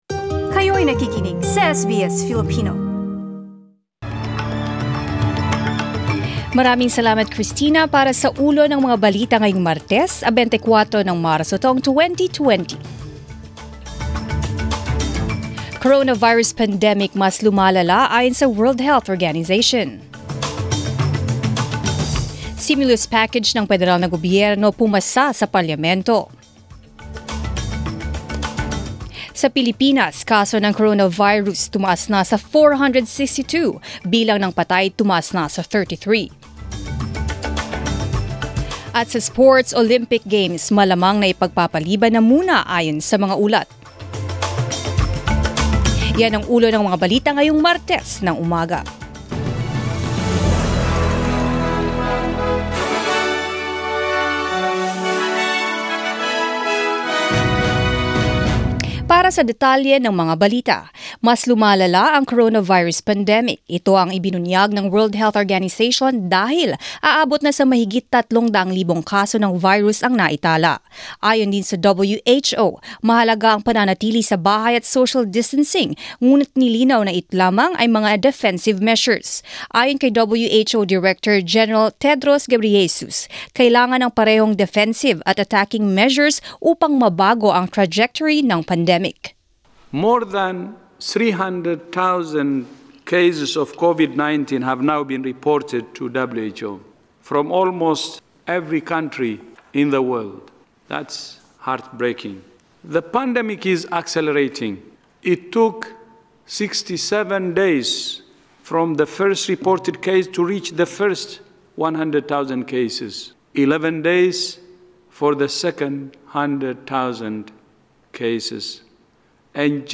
Mga balita ngayong ika- 24 ng Marso